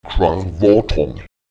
Kommt in einem Wort das Satzzeichen Lith () vor, so wird die diesem Zeichen unmittelbar folgende Silbe betont: